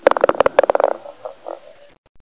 1 channel
RATTLE.mp3